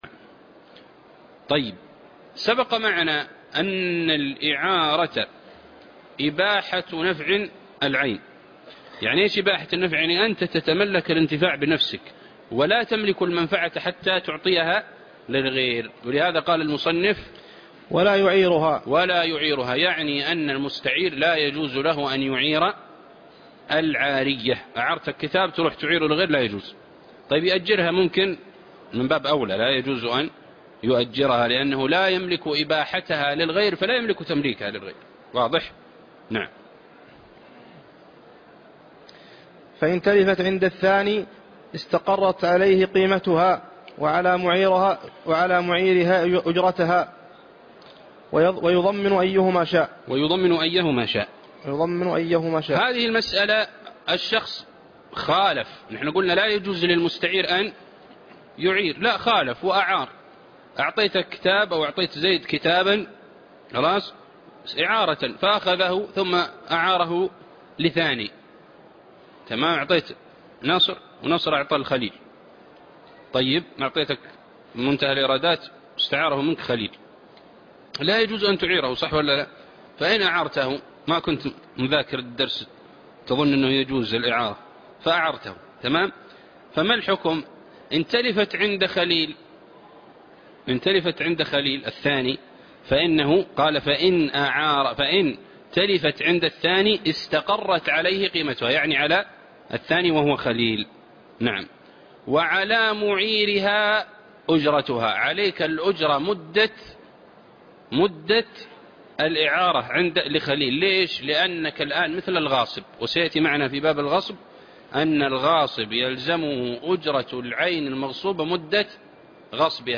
الدرس 90 - شرح زاد المستقنع ( تابع باب العارية )